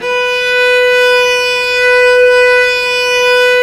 Index of /90_sSampleCDs/Roland L-CD702/VOL-1/STR_Vc Arco Solo/STR_Vc Arco p nv